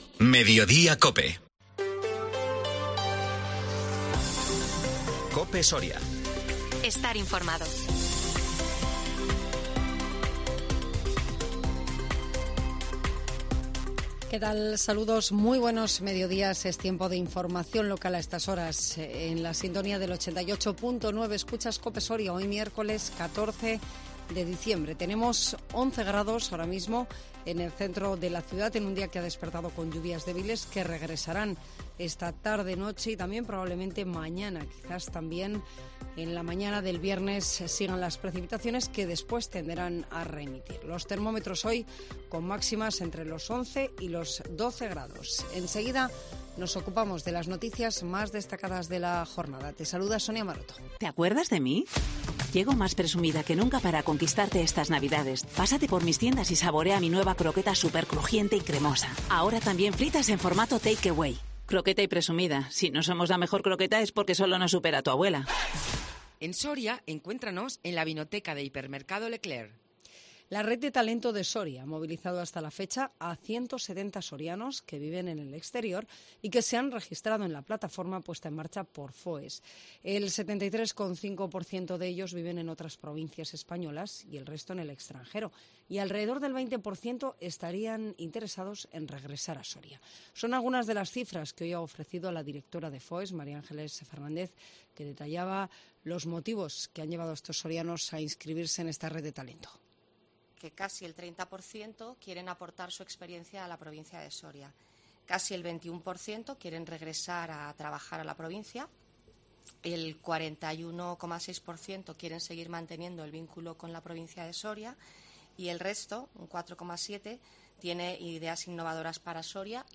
INFORMATIVO MEDIODÍA COPE SORIA 14 DICIEMBRE 2022